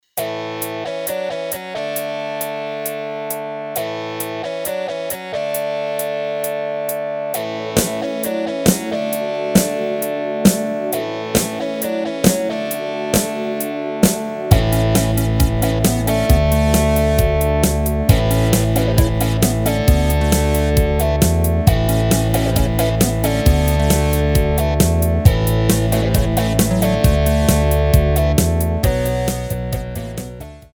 Rubrika: Pop, rock, beat
Karaoke
HUDEBNÍ PODKLADY V AUDIO A VIDEO SOUBORECH